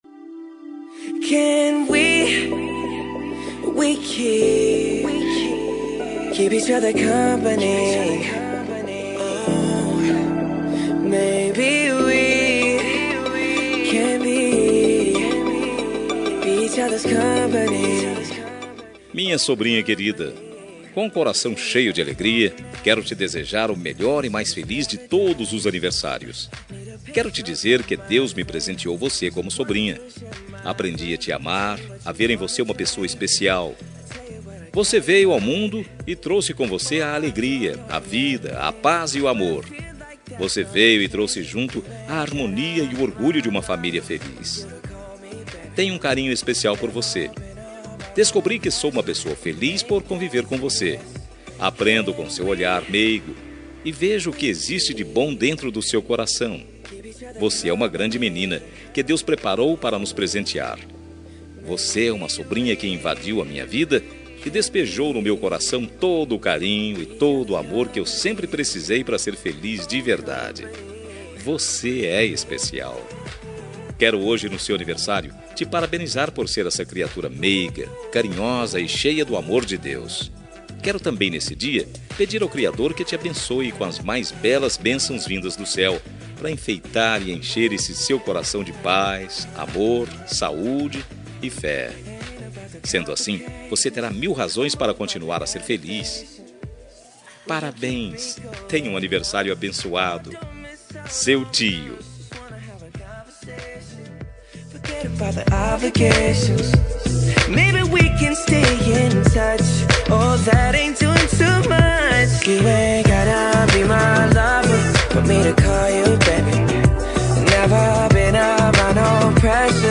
Aniversário de Sobrinha – Voz Masculina – Cód: 4286